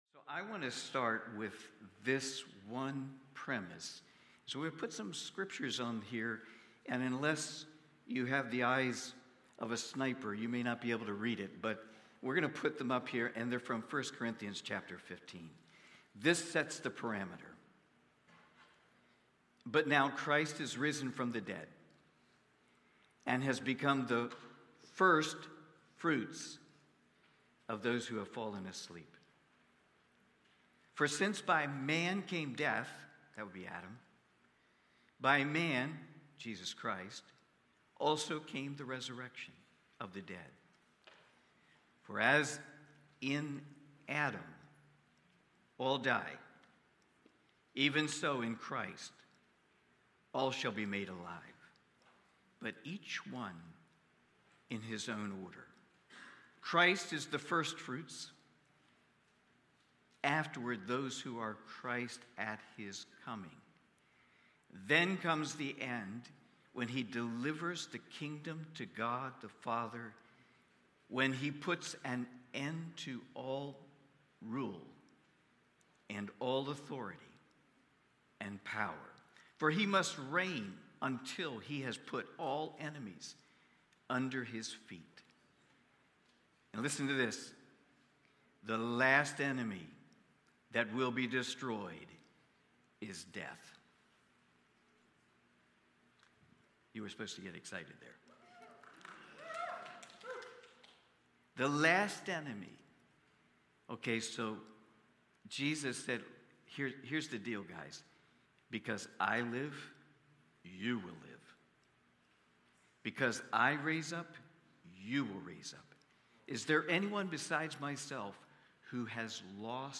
Daniel Watch Listen Save Cornerstone Fellowship Sunday morning service, livestreamed from Wormleysburg, PA.